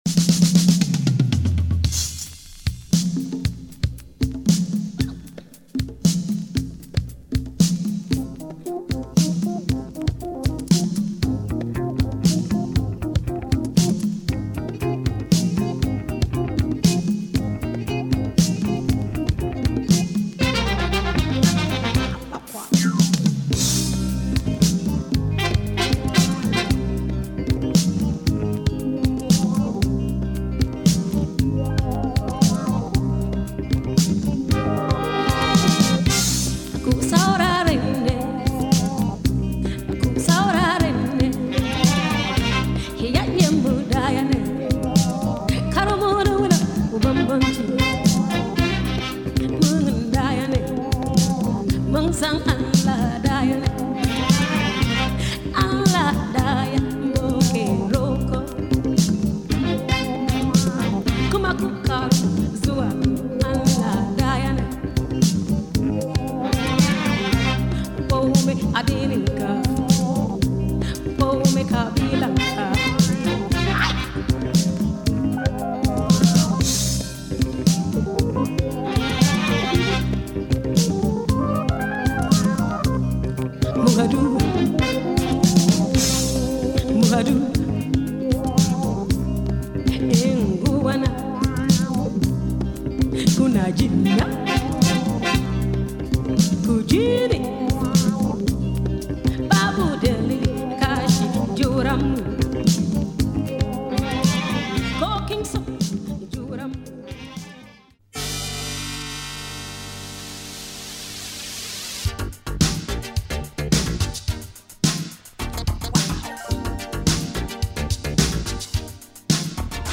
Killer Nigerian modern groove